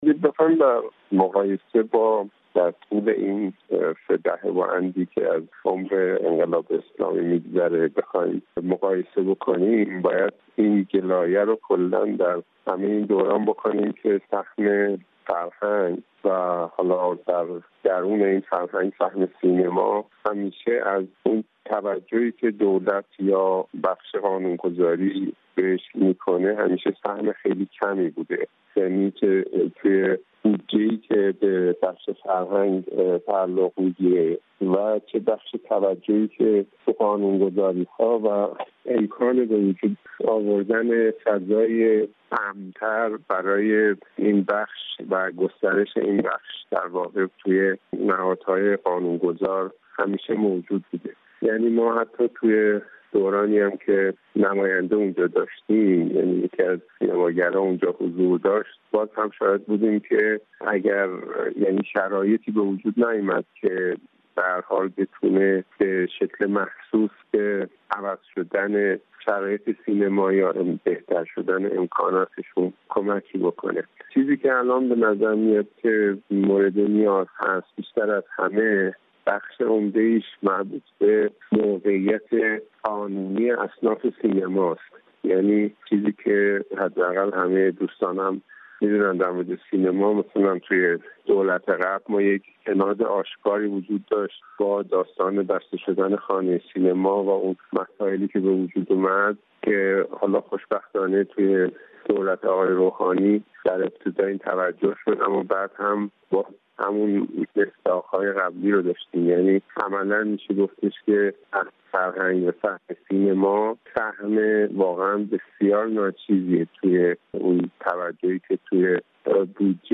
در آستانه انتخابات روز جمعه در ایران، برخی چهره‌های فرهنگی و هنری از جمله تعدادی از سینماگران شناخته شده خواستار شرکت مردم در انتخابات شده‌اند. درباره مطالبات و توجه چهره‌های فرهنگی و هنری به انتخابات روز جمعه، رادیو فردا با ابوالحسن داودی، گفت‌وگو کرده است.